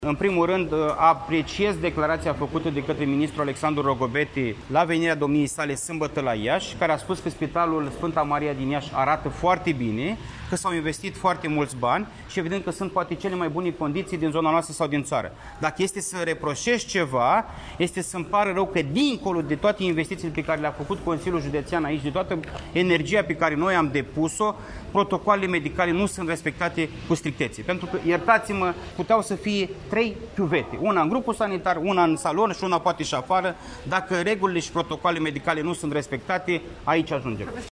Preşedintele Consiliului Judeţean Iaşi, Costel Alexe, a declarat, astăzi, în cadrul unei conferinţe de presă susţinute la Spitalul de Copii „Sf. Maria”, că proiectul de modernizare prin eficientizare energetică a unităţii medicale a fost implementat respectând specificaţiile tehnice care au fost avizate de specialişti, inclusiv cei ai Direcţiei de Sănătate Publică Iaşi.